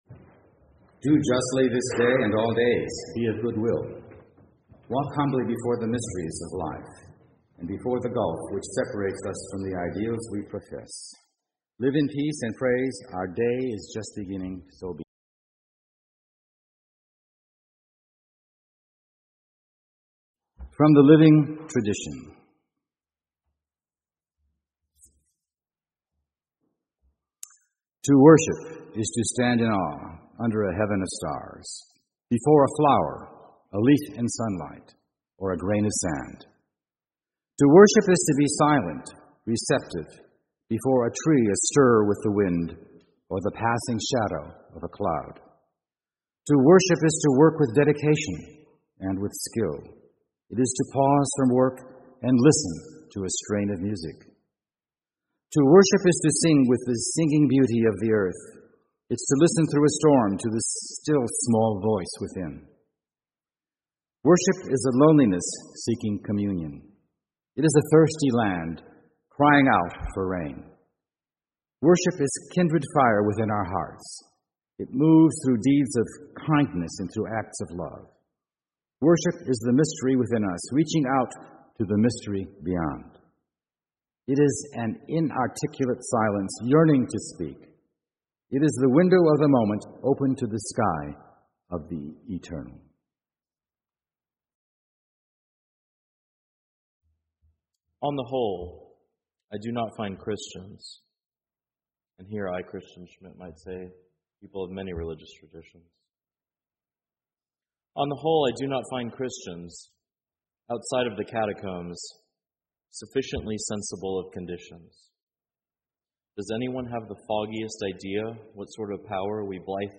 2019 Text of this sermon is not available.